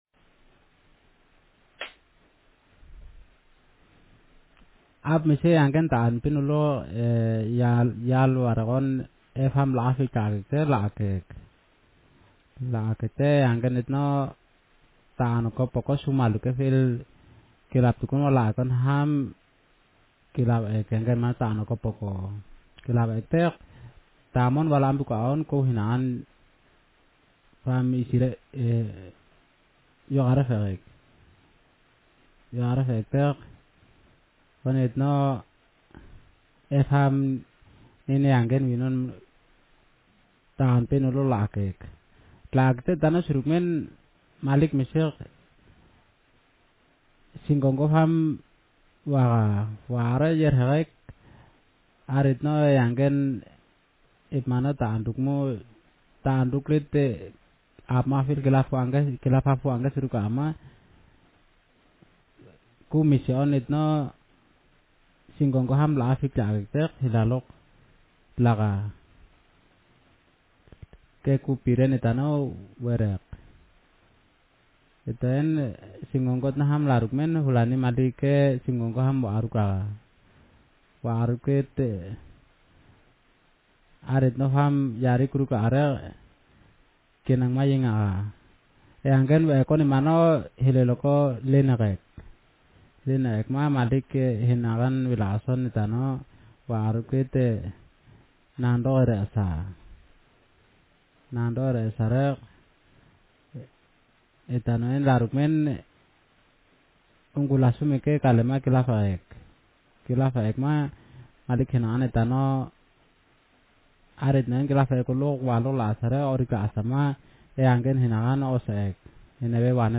Speaker sexm
Text genrestimulus retelling